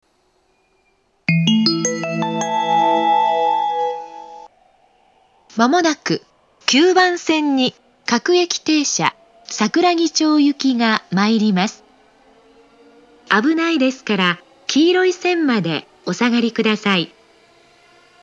９番線接近放送
発車メロディー（教会の見える駅）
標準的な戸閉放送でした。